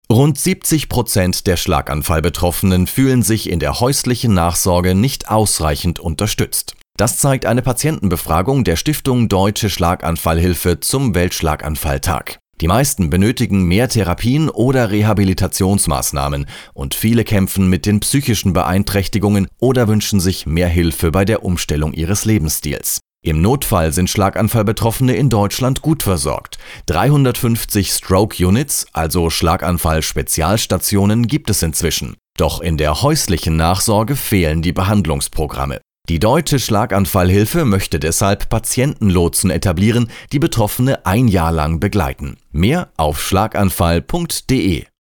2024__radiobeitrag_weltschlaganfalltag_patientenlotsen.mp3